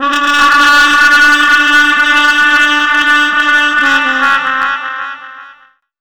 08_Miles_160bpm.wav